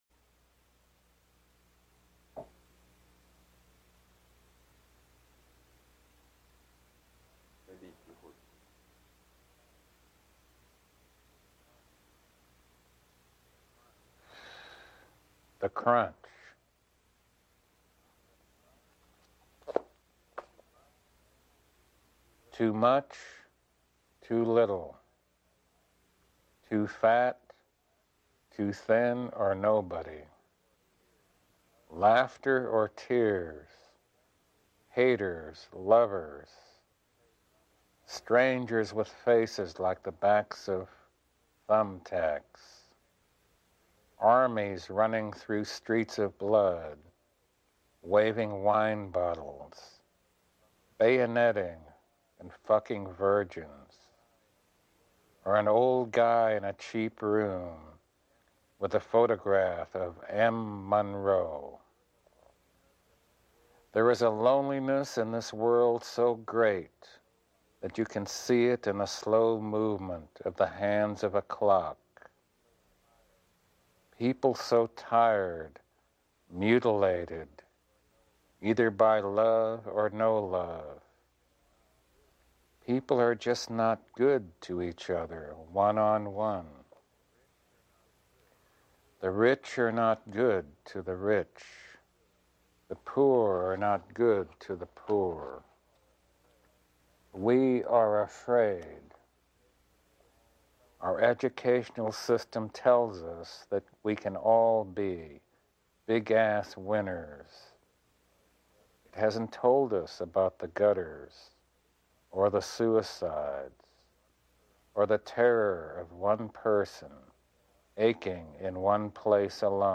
This time-consuming project, made between 2020 and 2021, is a sound collage based on the Persian translation of Charles Bukowski’s poem “The Crunch,” which the poet edited twice in 1977 and once in 1999.
The source of the sounds is the revolutionary hymns of Iran in the years before and after the 1979 revolution, and 42 hymns have been utilized in this project.
The sounds have been put together layer by layer to reconstruct the translated text to make it whole. Each word may be composed of sounds found in several words taken from hymns. A general formula has been created for this reconstruction, specifying the entire collage in the phonetic alphabet with its references.
The sound is placed inside a simulated airplane black box and a phonetic monitor shows the words along with the Farsi script, word meanings, and collage formula one by one.